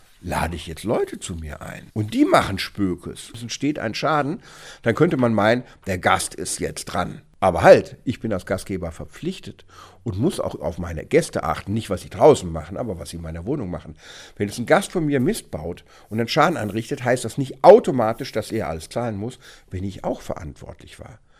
O-Ton Silvester3: Wer haftet, wenn Böller Schaden anrichten?